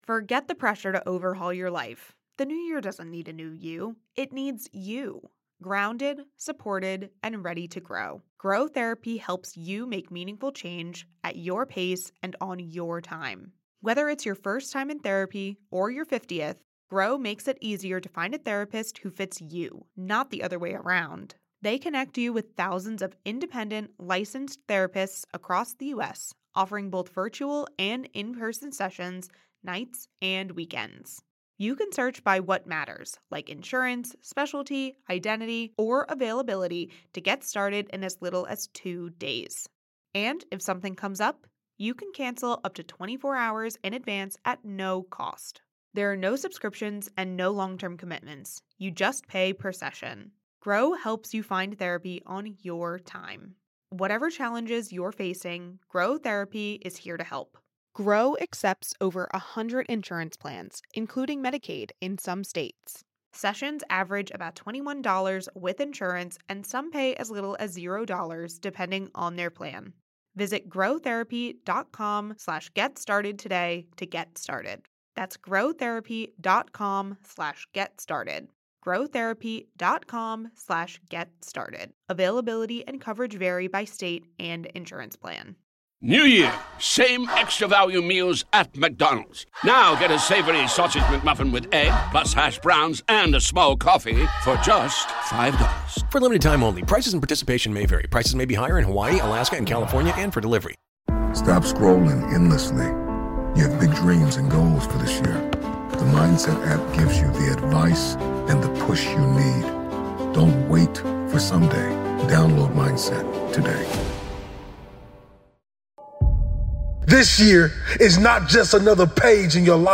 It's time to set a new course and become the person you were meant to be. Featuring new motivational speeches from Motiversity speakers